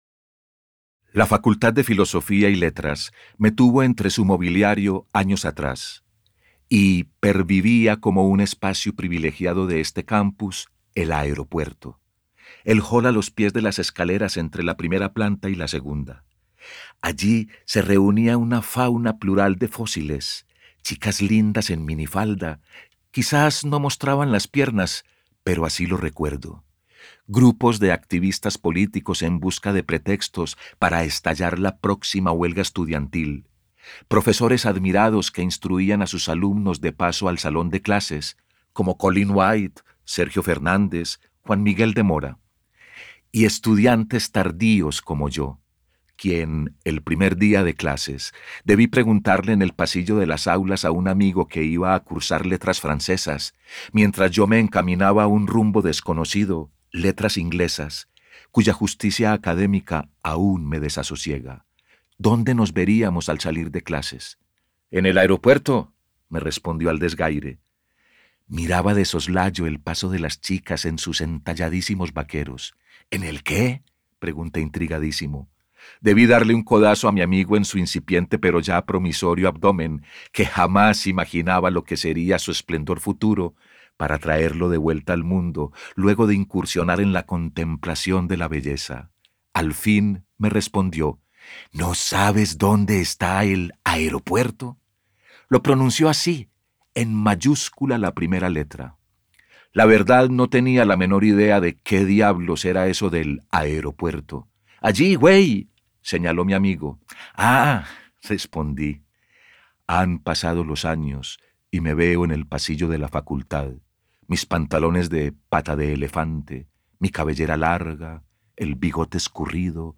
Masculino
Voz Padrão - Grave 02:04